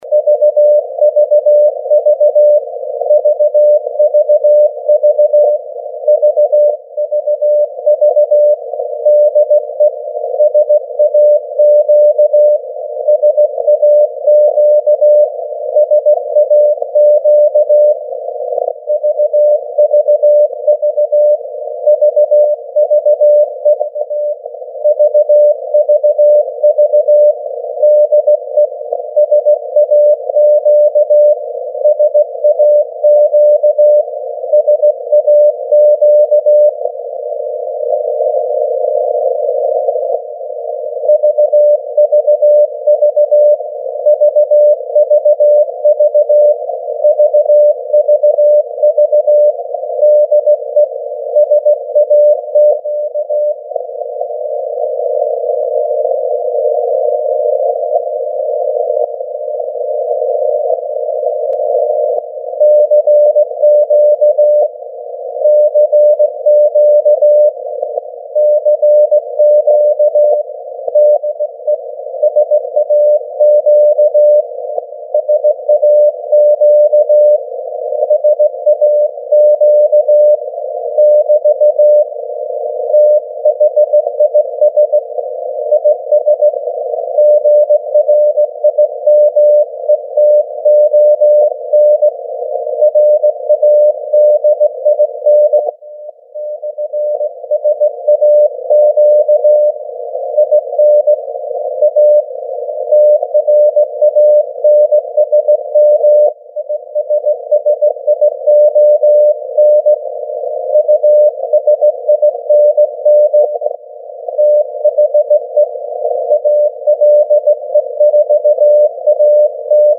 Mode: CW
Good signals here again this year, and a nice recording on the following link for your interest.
Receiver was my trusty Perseus SDR, with about 500m of beverage cable….
some funny little carrier sounding thing at the end as though something else tuning up, or tx warble…,.